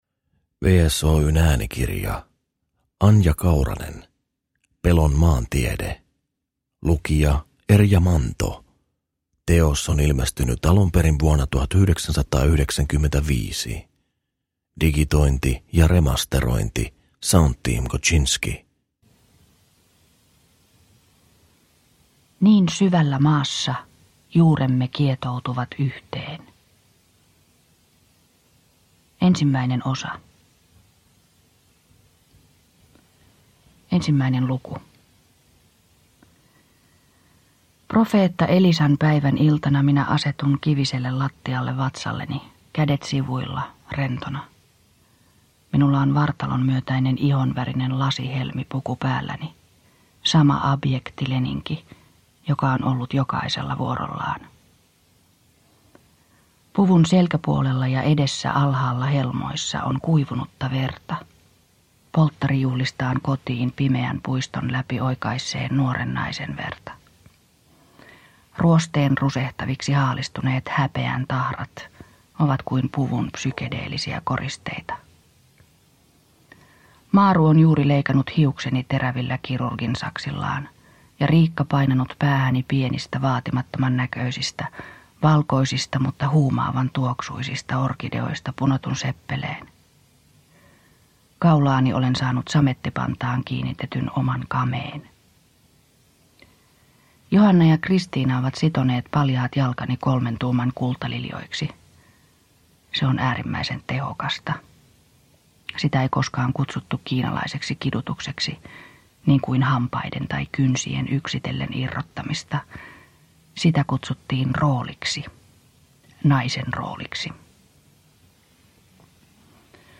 Pelon maantiede – Ljudbok – Laddas ner